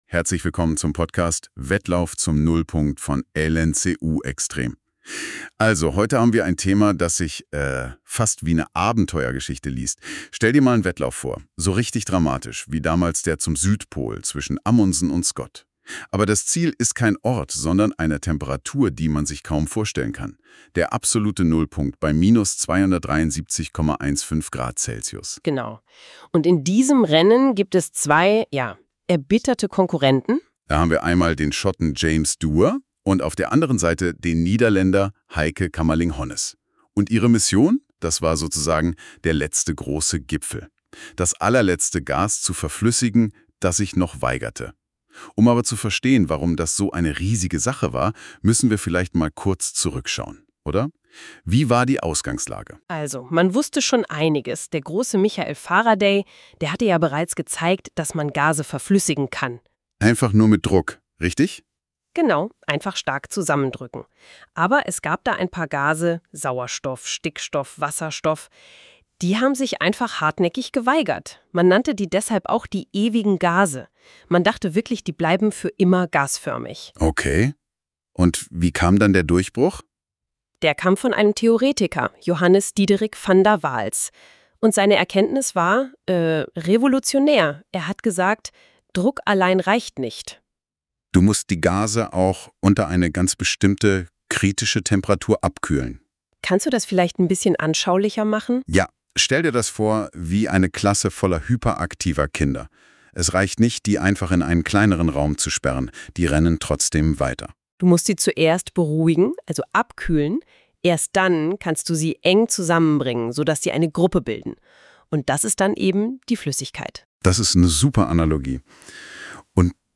Anstelle eines Textes hier der Audiokommentar einer KI zu unseren Rechercheergebnissen5. Der Kommentar ist nach unserem Dafürhalten – bis auf wenige Aussprachefehler bei Namen – sehr gelungen.